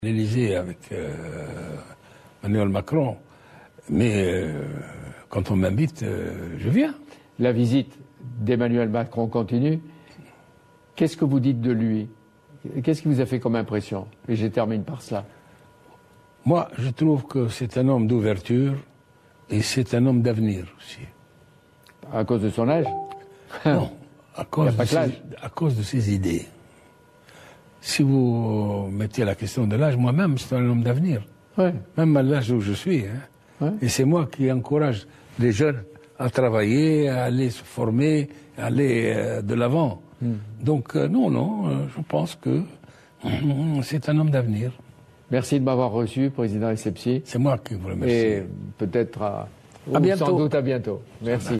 في ردّه على سؤال في حوار أجراه صباح اليوم مع قناة فرنسية، حول انطباعه بخصوص شخصية الرئيس الفرنسي ايمانويل ماكرون، اعتبر الباجي قايد السبسي أنه "رجل المستقبل".